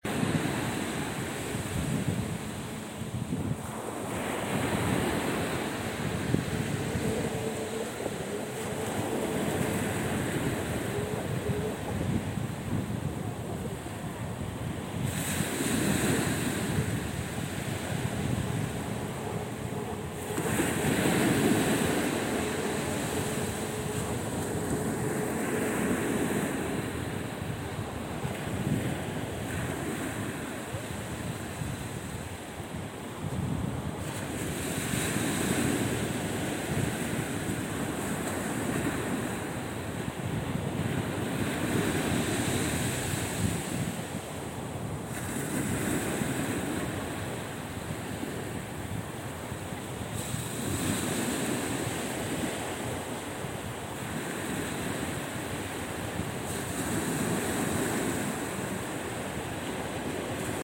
Un minuto de grabación del mar y la brisa en la orilla de la Playa de Mazagón (Huelva)
brisa
Sonidos: Agua